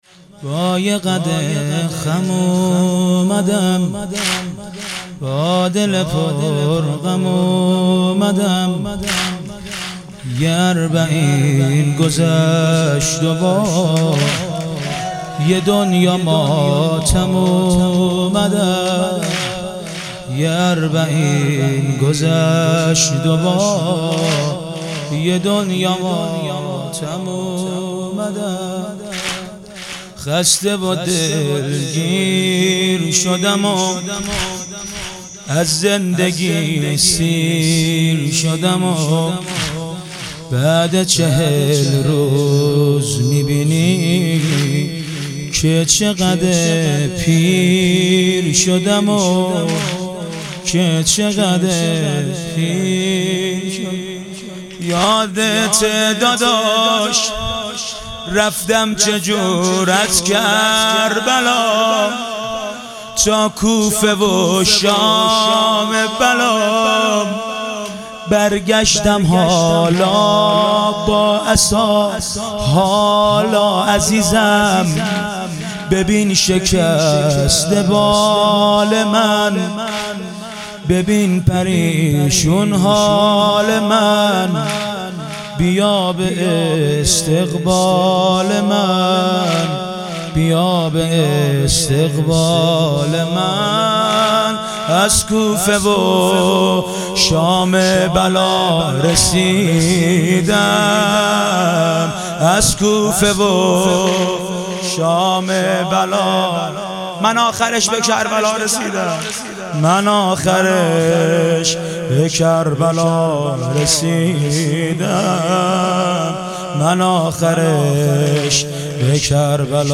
اربعین امام حسین علیه السلام - واحد